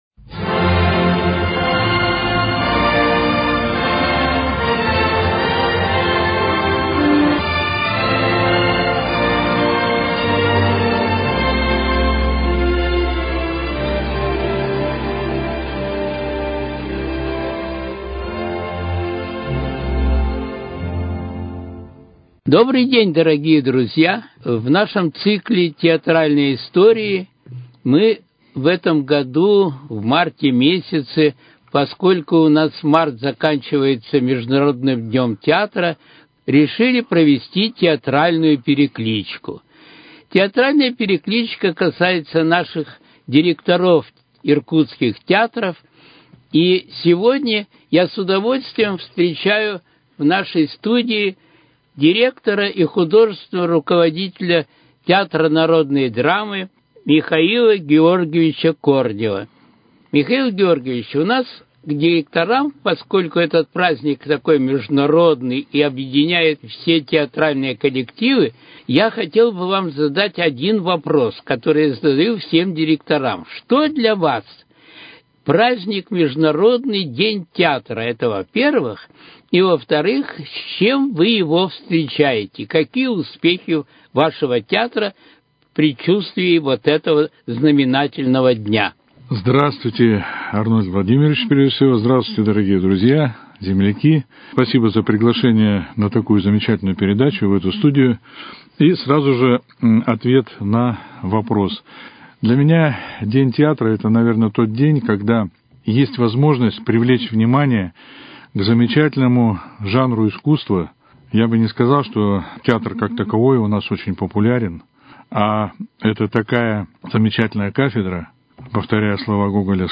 Мы продолжаем цикл передач, посвященных этому празднику. В них примут участие руководители Иркутских театров.